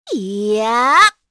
Shamilla-Vox_Casting2_kr.wav